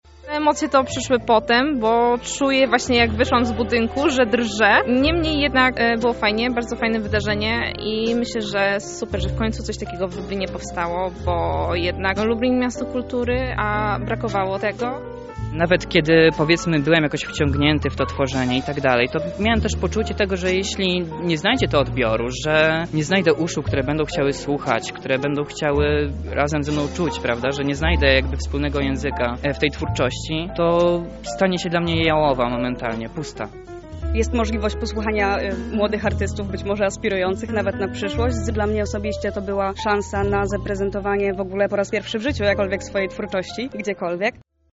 W ostatni czwartek w kawiarni Święty Spokój odbył się wieczorek poetycki.
O emocjach towarzyszących spotkaniu rozmawialiśmy z autorami: